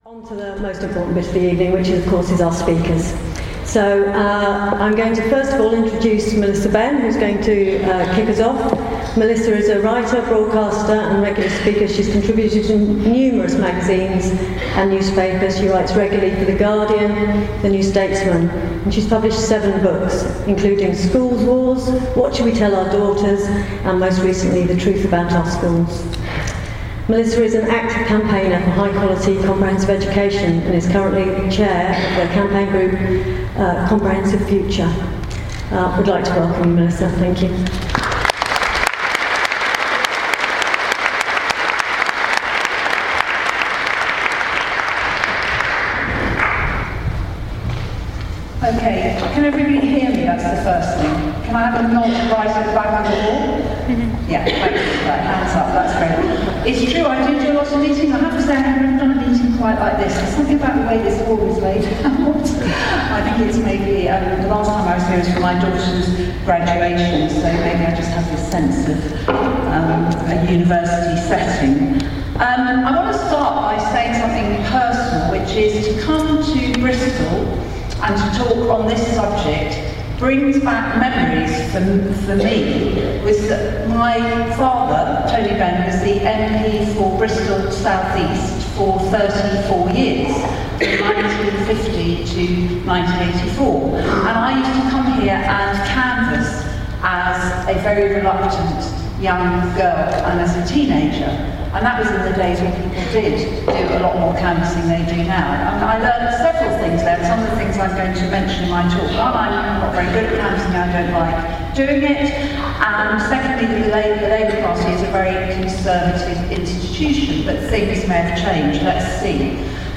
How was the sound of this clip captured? Speaking on the future of Social Justice, at the Bristol Festival of Ideas and Policy Press Evening, University of Bristol, December 5th.